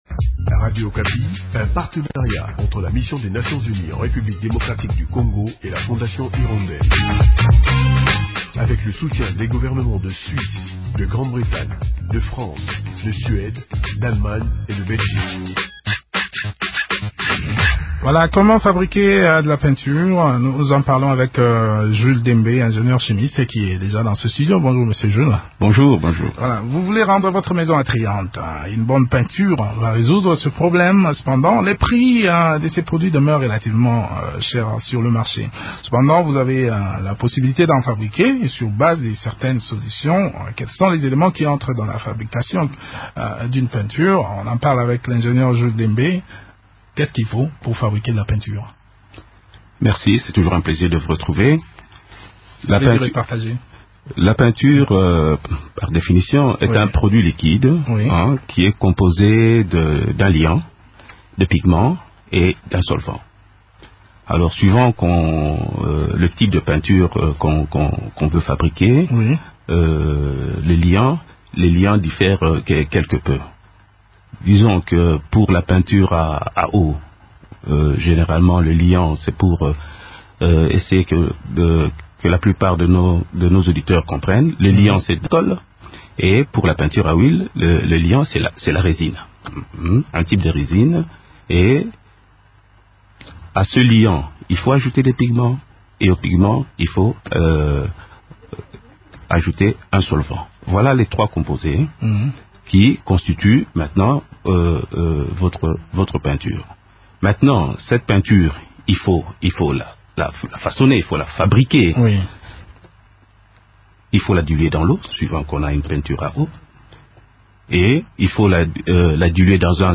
ingénieur chimiste